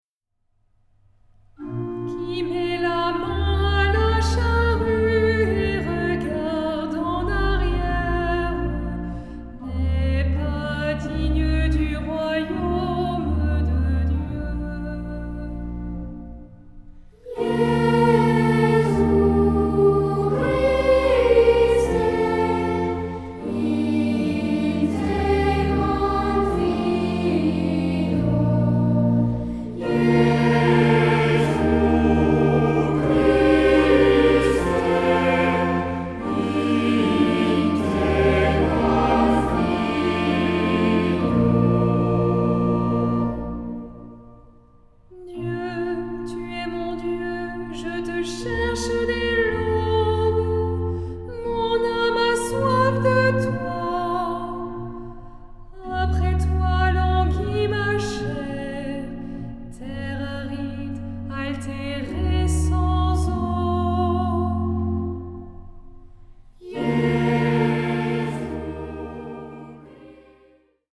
SAH O SATB (4 voces Coro mixto) ; Partitura general.
Salmodia.